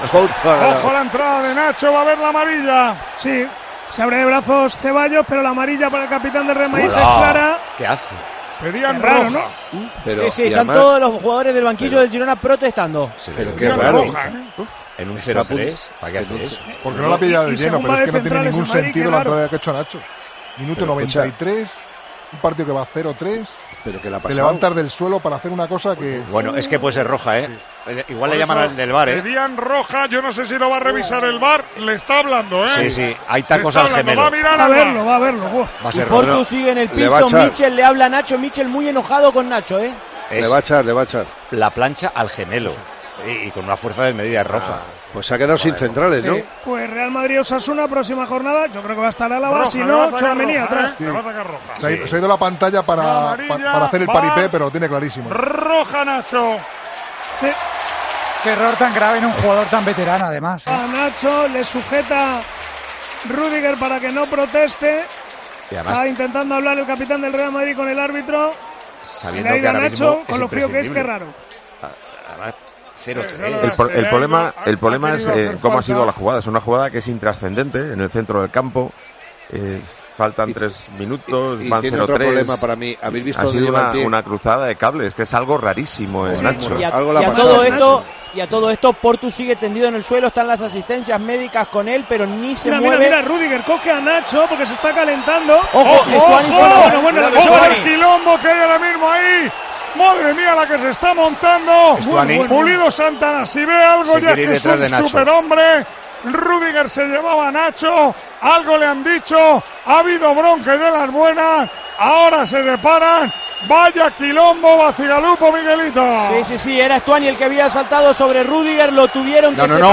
En medio de todo el lío el público de Montilivi ha empezado a gritar "asesino, asesino, asesino" al central.